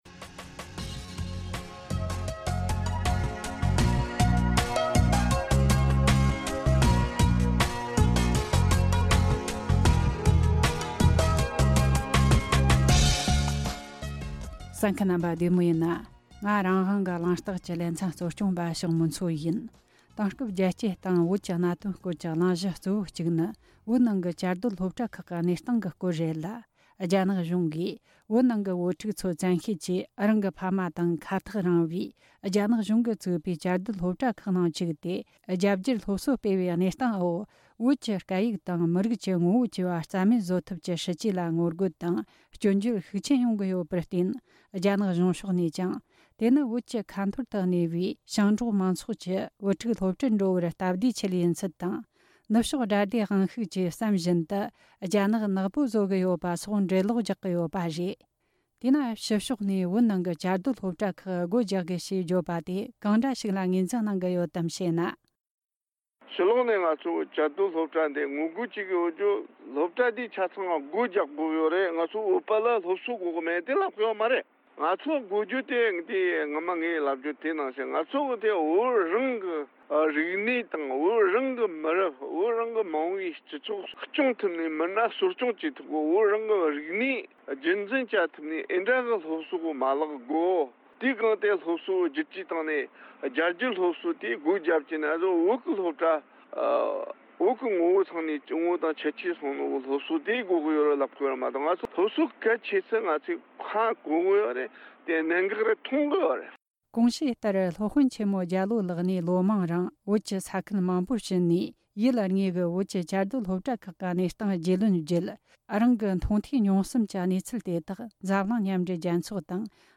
ཁོང་ལ་བཅར་འདྲི་བྱས་བར་གསན་རོགས་གནོངས།